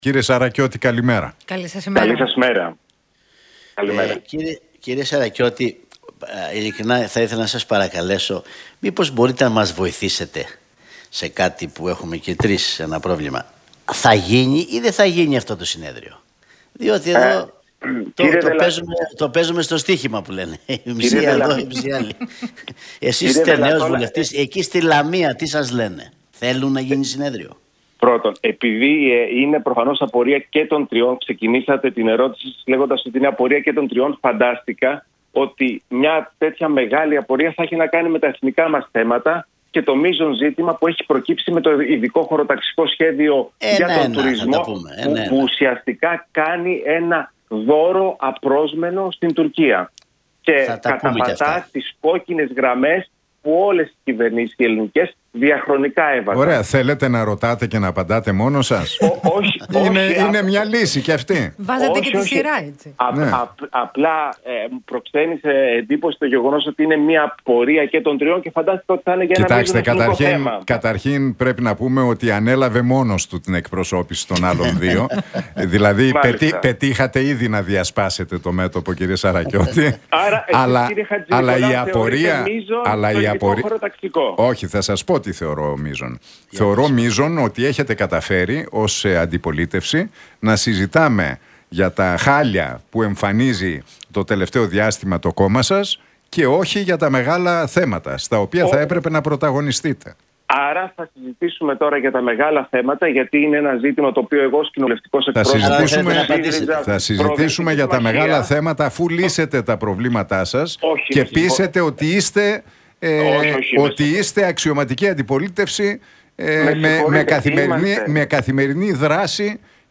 Σαρακιώτης στον Realfm 97,8: Πρέπει να γίνει άμεσα συνέδριο χωρίς κανέναν αποκλεισμό